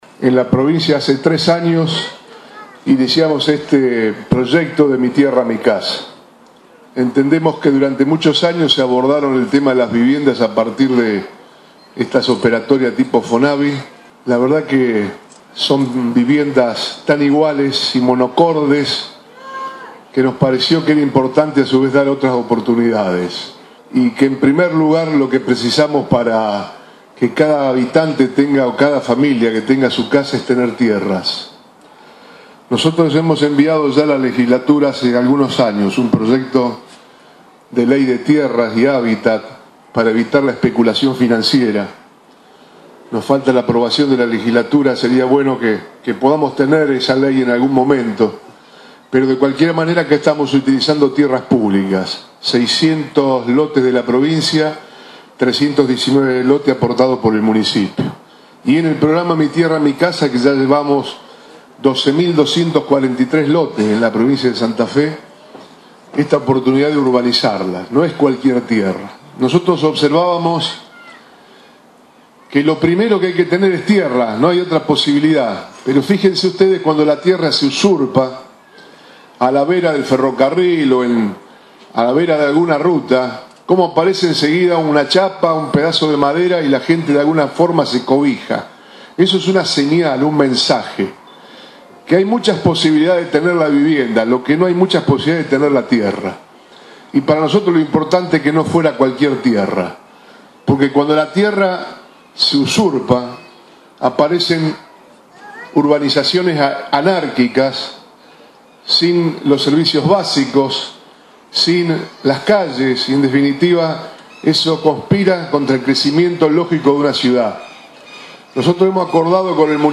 Obras Públicas El gobernador Antonio Bonfatti durante la entrega de actas de adjudicación de parcelas a 501 familias en la ciudad de Rafaela.